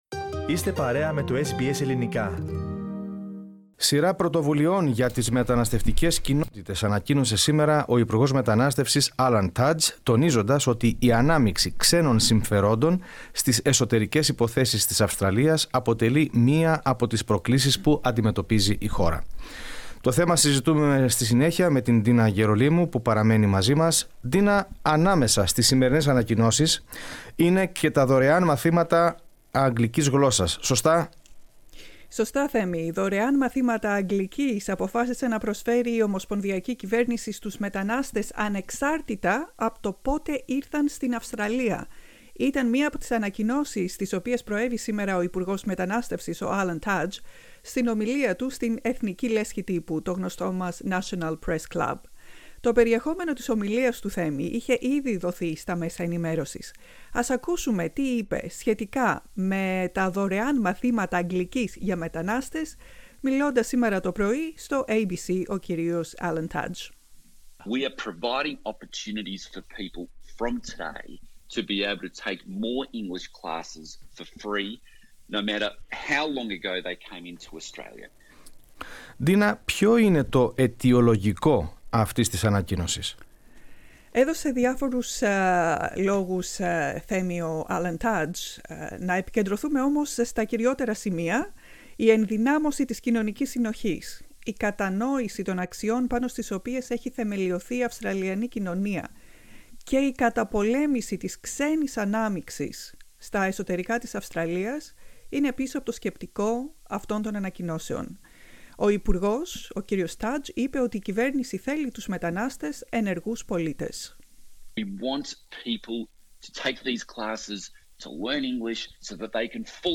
Ας ακούσουμε τι είπε μιλώντας σήμερα το πρωί στο ABC σχετικά με τα δωρεάν μαθήματα αγγλικής για μετανάστες.